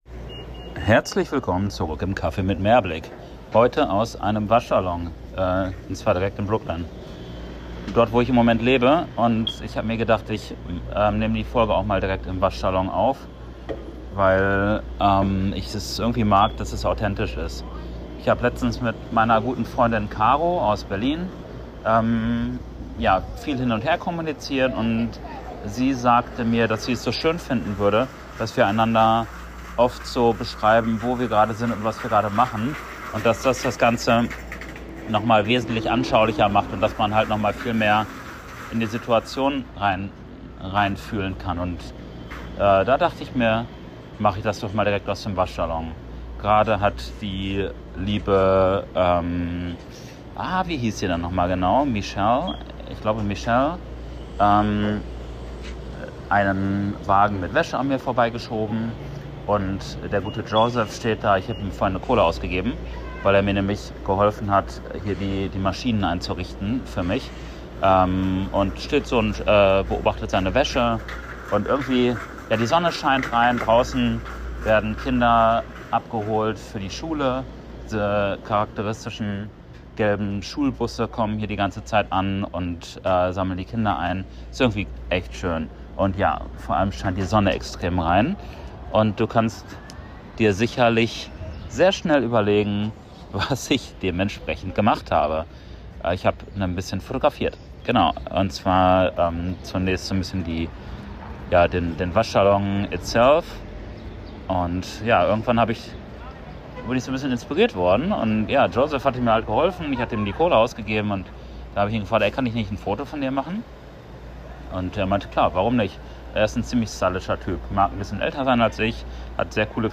Eine Stunde am frühen Morgen im Waschsalon in Brooklyn.